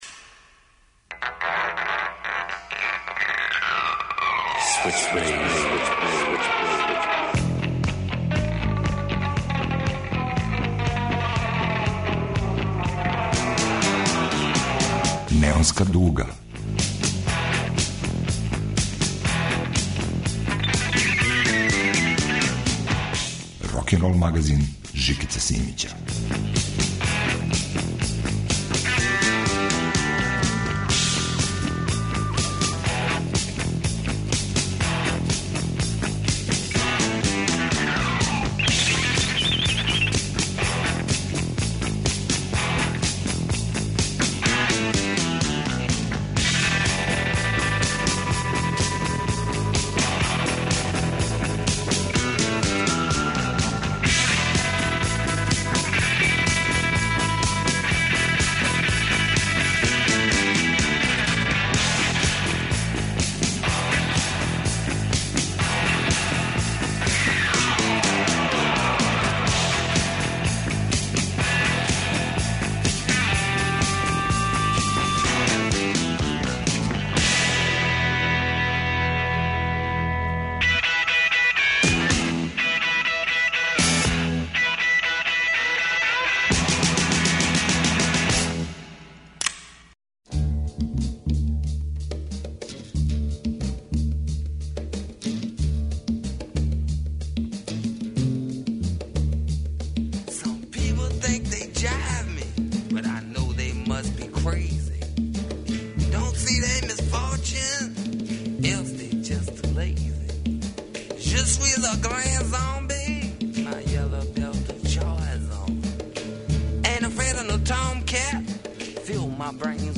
Рокенрол као музички скор за живот на дивљој страни. Вратоломни сурф кроз време и жанрове.